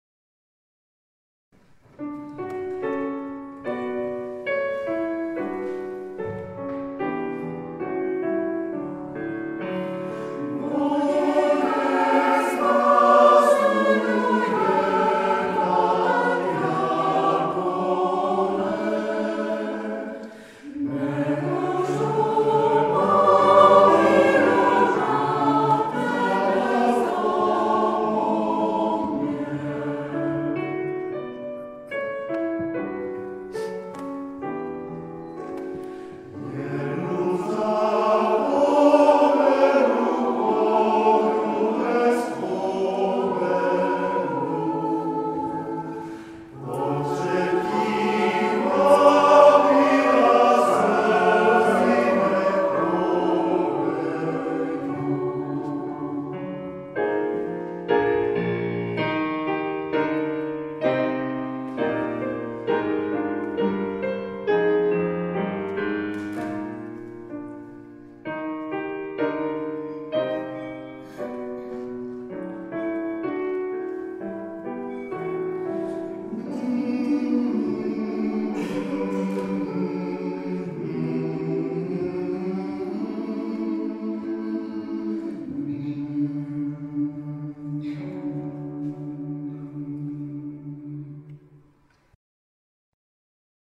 Zvuková ukázka z vystoupení v kostele Panny Marie v Železné Rudě